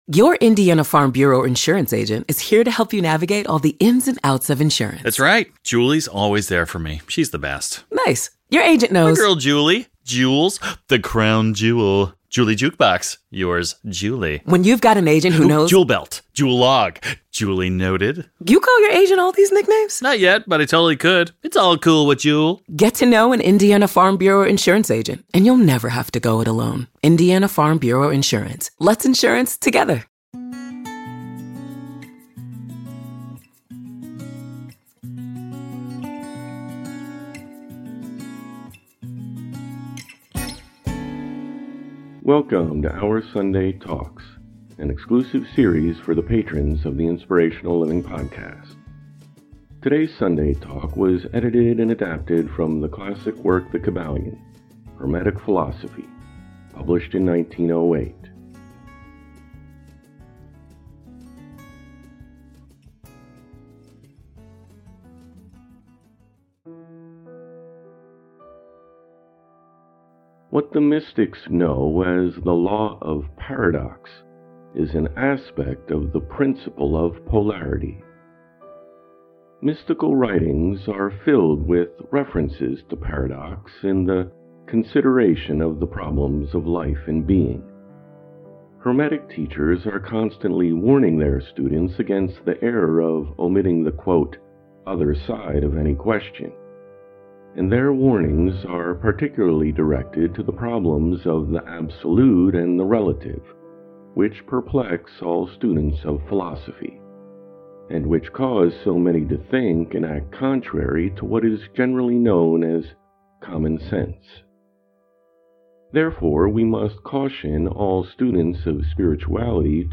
Look no further than the most motivational self-help authors of the past. Inspiring readings from James Allen, Napoleon Hill, Hellen Keller, Booker T. Washington, Khalil Gibran, Marcus Aurelius, and more.